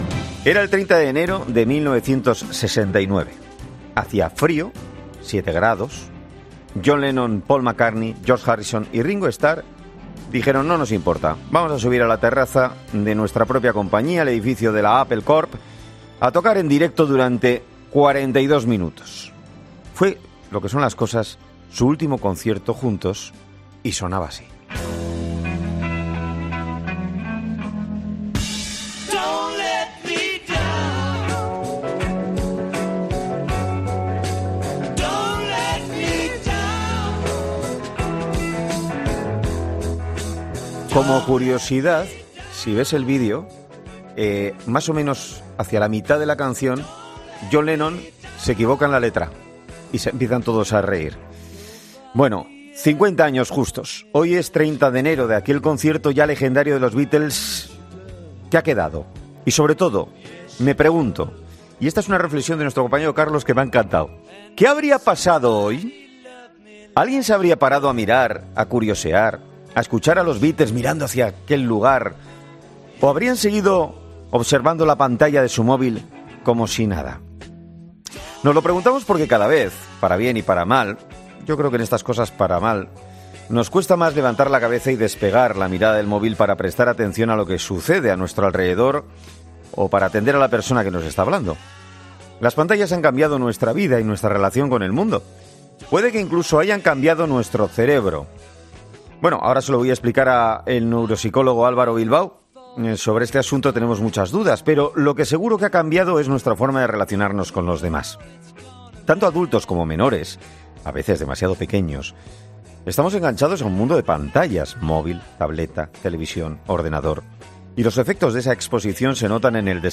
Expertos consultados por Cope hablan sobre la edad recomendada para tener móvil o sobre cómo las nuevas tecnologías están afectando al cerebro